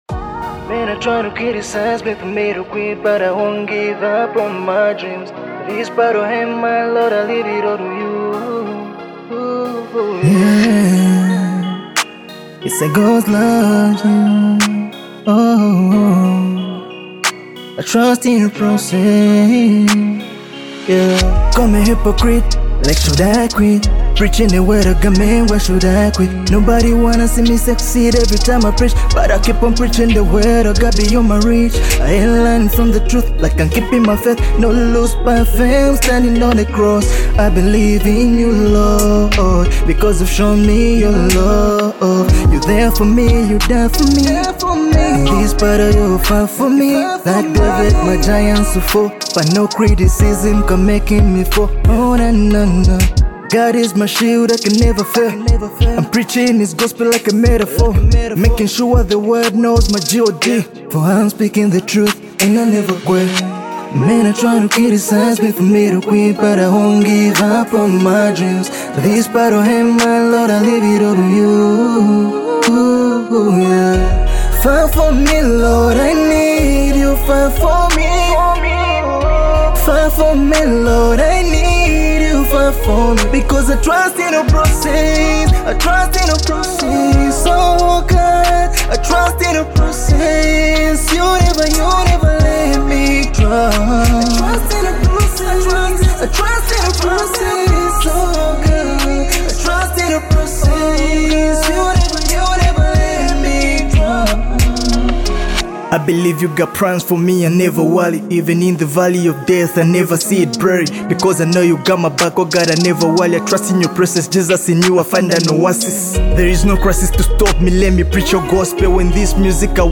Music Gospel